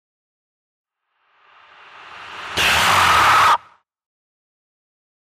Alien Screech Scream 3 - Monster Dinosaur